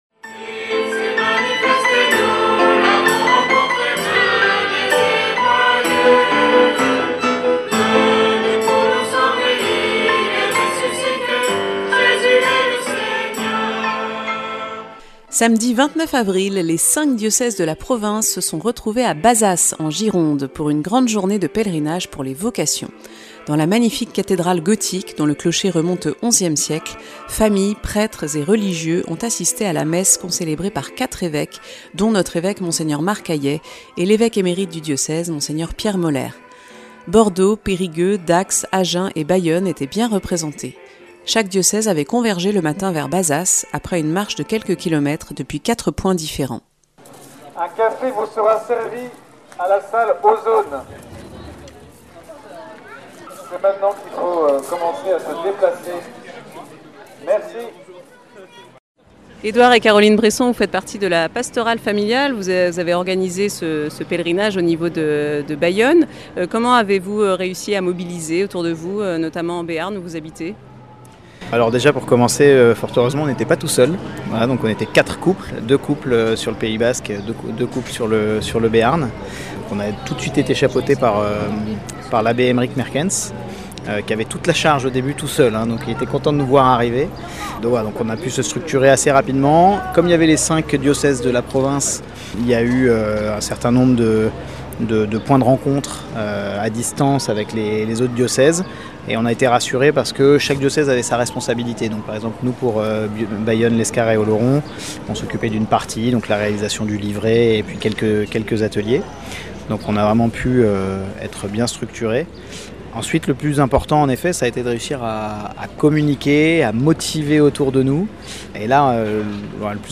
Un reportage au Pèlerinage des familles à Bazas le 29 avril